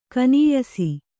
♪ kanīyasi